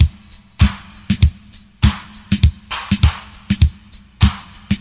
100 Bpm Breakbeat D Key.wav
Free drum beat - kick tuned to the D note. Loudest frequency: 1167Hz
.WAV .MP3 .OGG 0:00 / 0:05 Type Wav Duration 0:05 Size 829,76 KB Samplerate 44100 Hz Bitdepth 16 Channels Stereo Free drum beat - kick tuned to the D note.
100-bpm-breakbeat-d-key-H3U.ogg